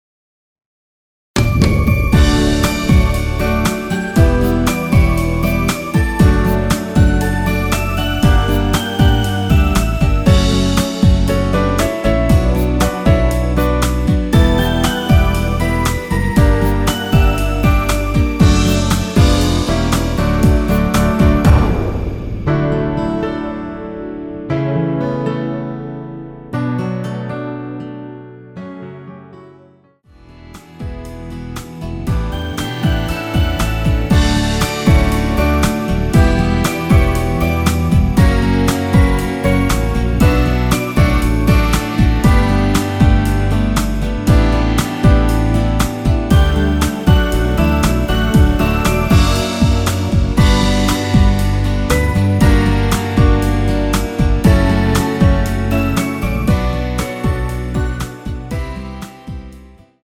편곡 MR입니다.
원키에서(-1)내린 1절후 바로 후렴부분으로 진행되게 편곡 하였습니다.
◈ 곡명 옆 (-1)은 반음 내림, (+1)은 반음 올림 입니다.
앞부분30초, 뒷부분30초씩 편집해서 올려 드리고 있습니다.